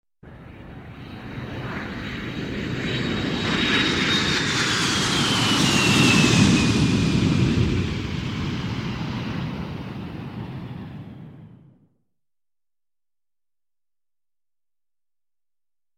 аэровокзал звуки скачать, слушать онлайн ✔в хорошем качестве